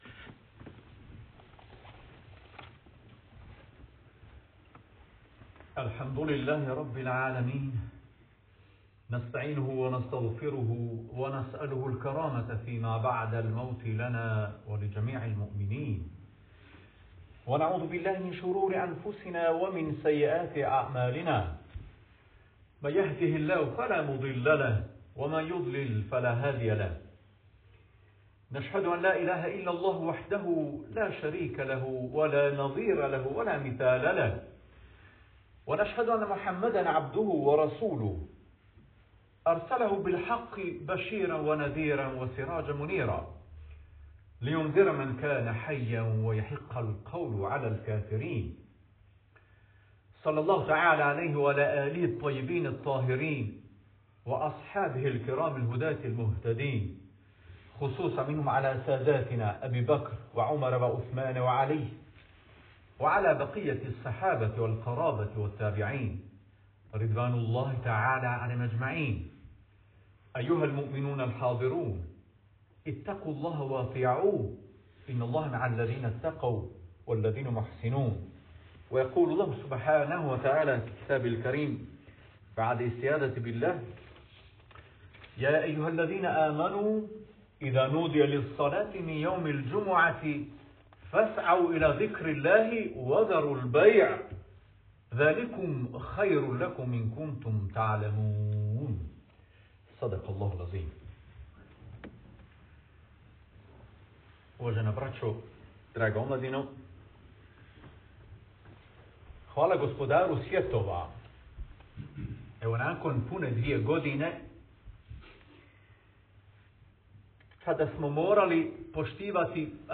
Hutba: Džuma namaz (Audio)